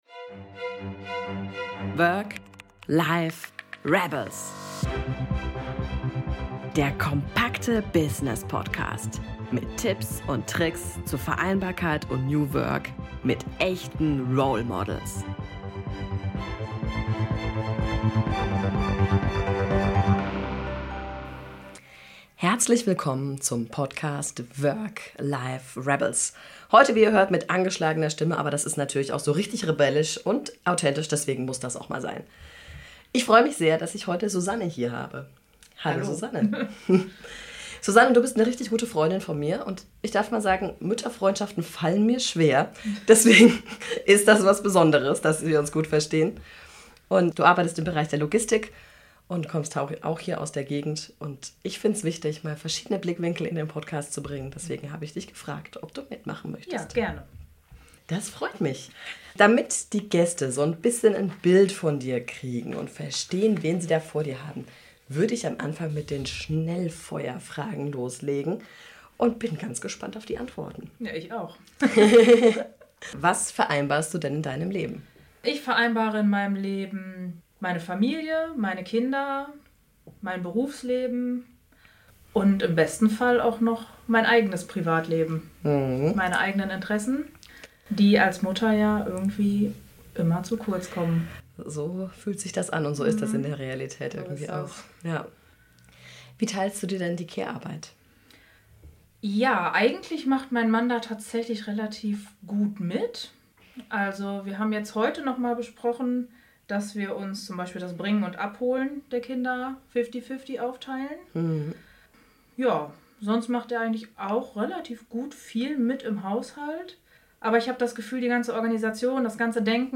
Beschreibung vor 1 Jahr In der Episode #6 des Podcasts Work Life Rebels habe ich erstmalig eine Inkognito-Gästin dabei. Sie erzählt aus ihrem Leben als Mutter zweier Kinder und Angestellter in der Logistik. Wir thematisieren spannende Themen, darunter wie meine Gästin nach einer Kündigung in der Elternzeit selbstbestimmt und mit Blick auf die eigenen Stärken den Arbeitgeber gewechselt hat.